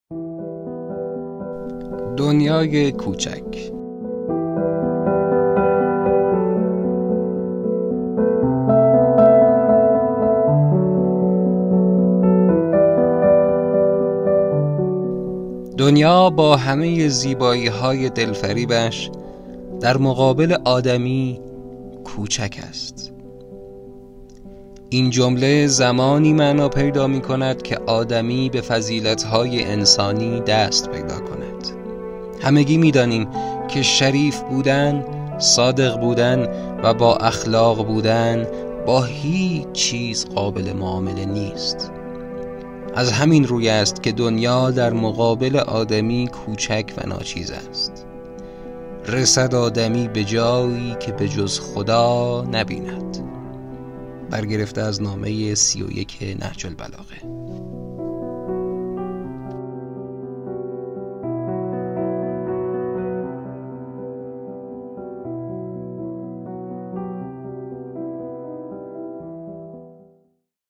دنیا با همه زیبایی‌های دلفریبش در مقابل آدمی کوچک است این جمله زمانی معنا پیدا می‌کند که آدمی به فصیلت‌های انسانی دست پیدا کند. خبرگزاری ایکنا بر آن است که قسمتی از خطبه‌های نهج‌البلاغه را به صورت صوتی تقدیم مخاطبان عزیز کند.